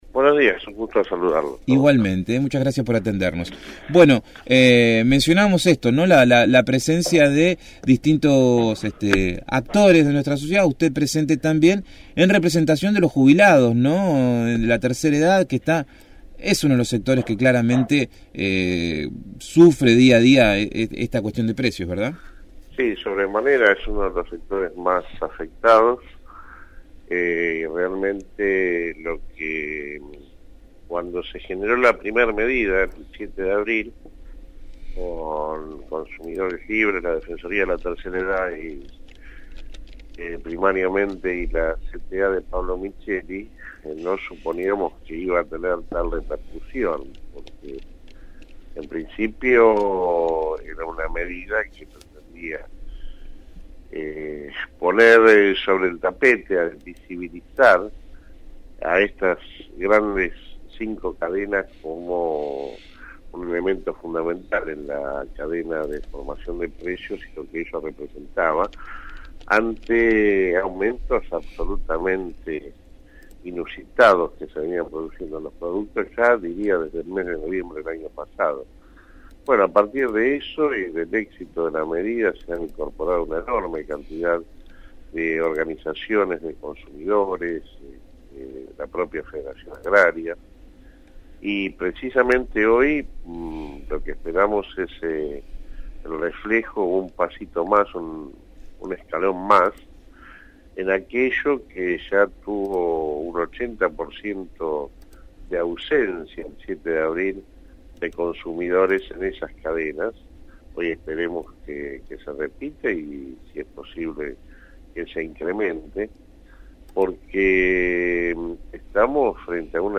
dialogó con el equipo de «El Hormiguero» sobre el boicot a la cadena de supermercados organizado para el día de hoy por ese organismo, en conjunto con Consumidores Libres y otras entidades. “El Hormiguero”/ FM 107.5 Conducción